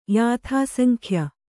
♪ yāthāsankhya